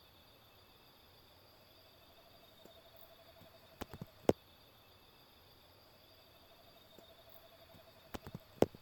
Alilicucú Orejudo (Megascops sanctaecatarinae)
Nombre en inglés: Long-tufted Screech Owl
Fase de la vida: Adulto
Localidad o área protegida: Parque Provincial Caá Yarí
Condición: Silvestre
Certeza: Fotografiada, Vocalización Grabada